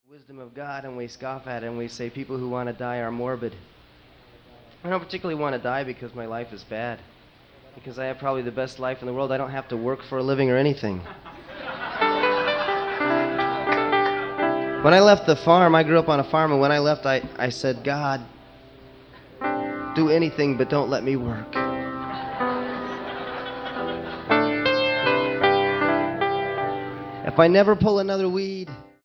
STYLE: Roots/Acoustic
Live